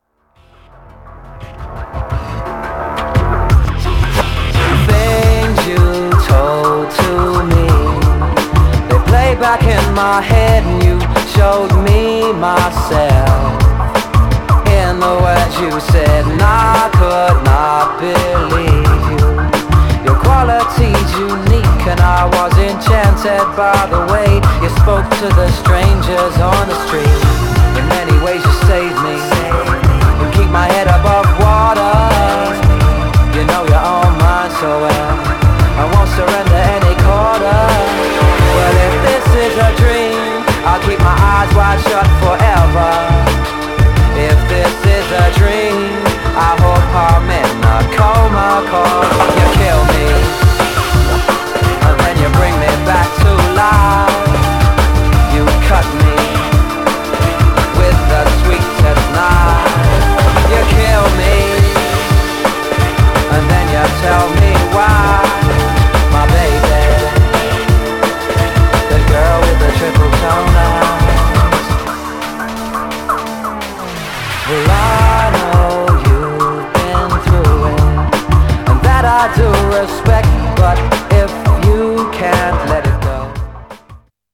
Styl: Drum'n'bass, Lounge, Breaks/Breakbeat